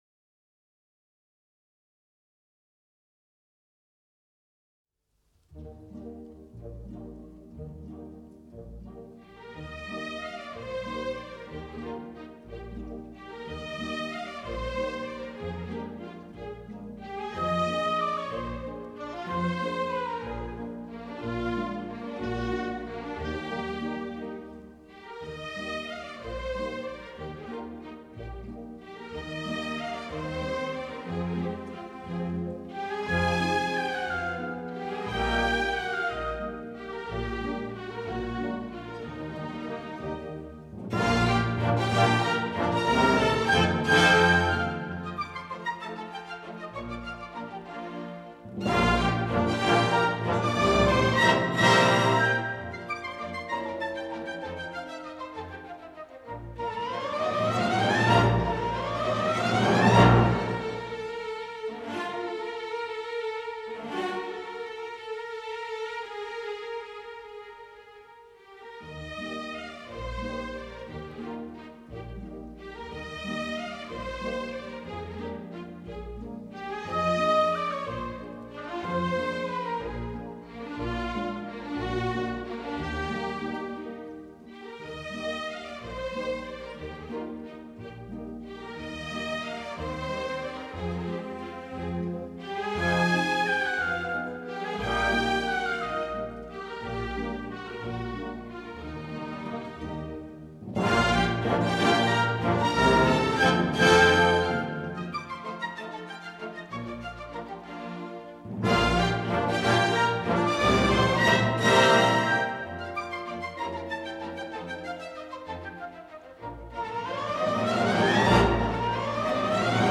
专辑音乐：古典